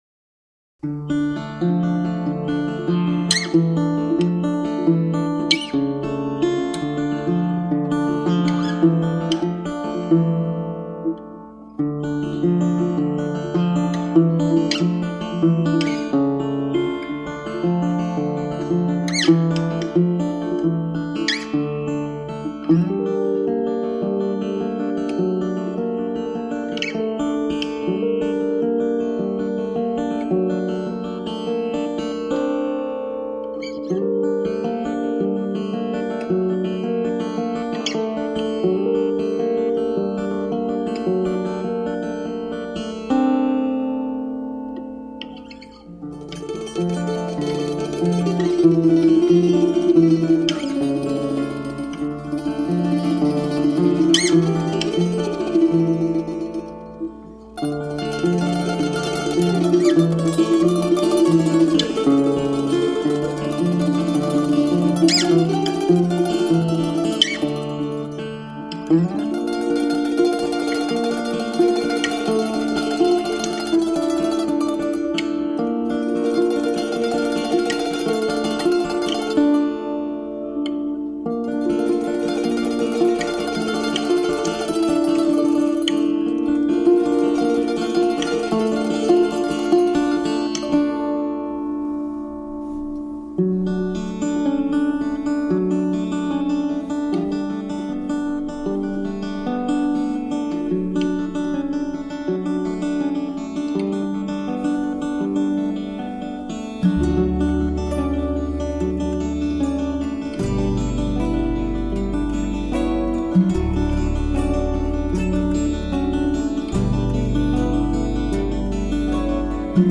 Music played by hand on instruments made of wood.
Tagged as: World, New Age